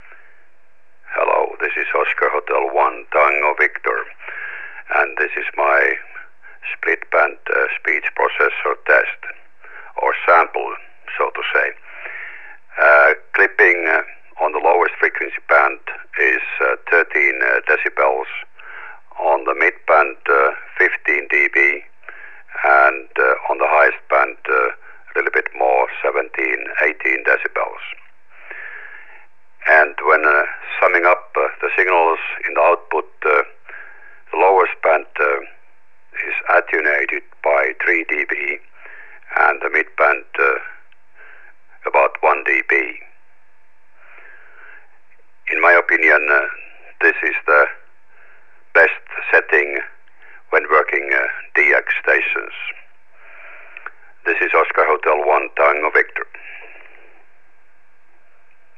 Split band audio signal processor 2007 for SSB and AM I build my first split band speech processor already in 1976.
The idea in split band processor concept is to avoid harmonic and intermodulation products, minimize distortion.
split band sample.wav